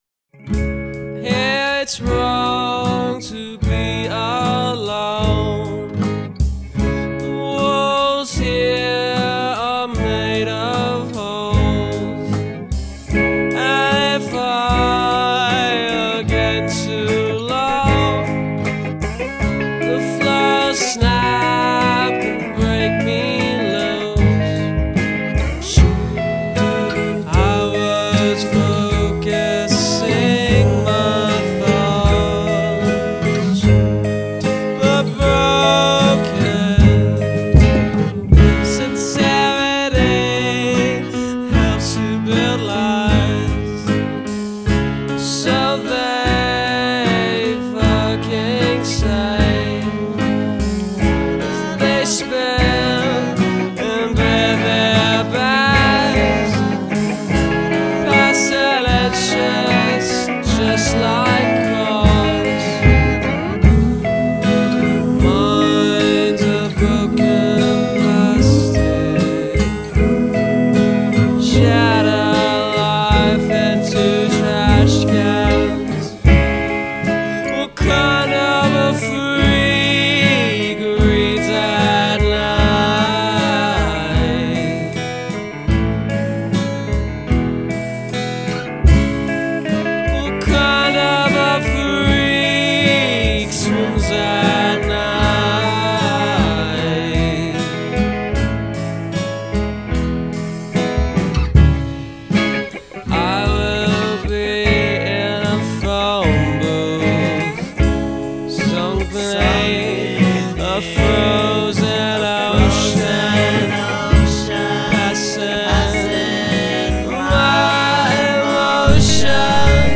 indie rock band
Bonus tracks and remixes